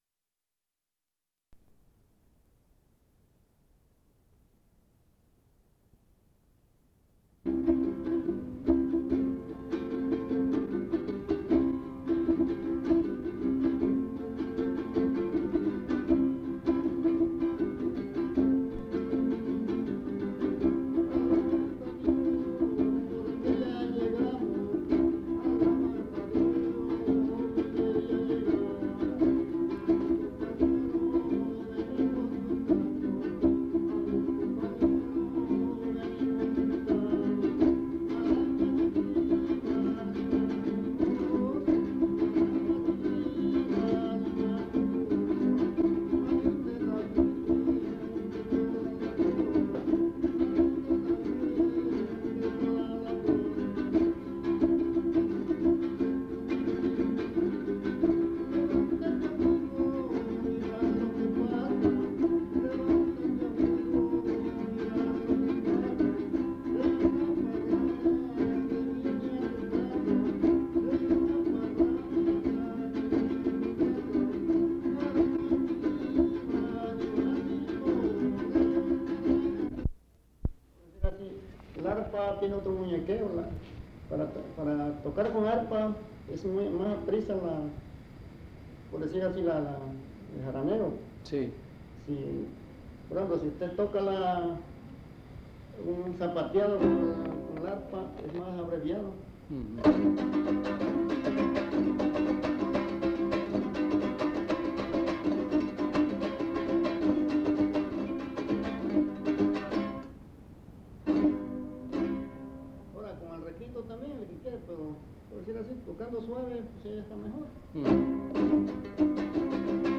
• La entrevista contiene fragmentos de canciones navideñas. El ambiente es de una posada.